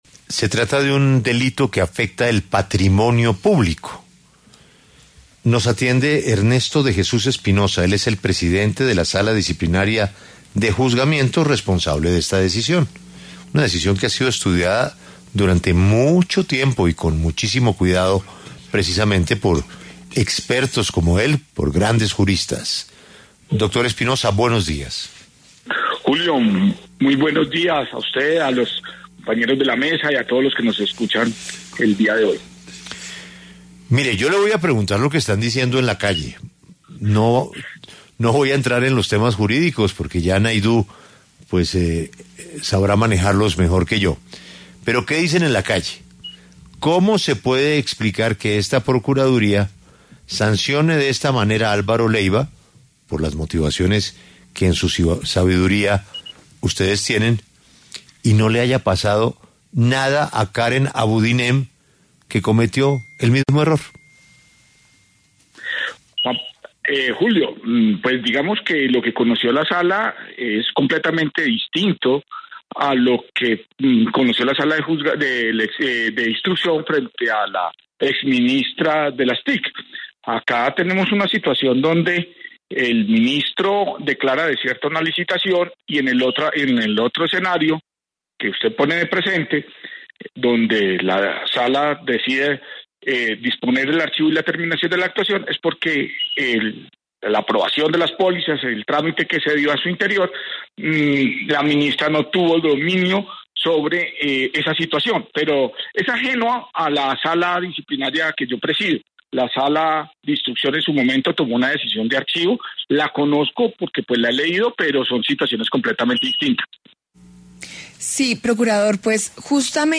El procurador Ernesto de Jesús Espinosa explicó en La W que, a diferencia del caso de la exministra Abudinen, quien no fue sancionada, el excanciller Leyva asumió responsabilidad directa en las decisiones del proceso, justificando así la inhabilidad de 10 años.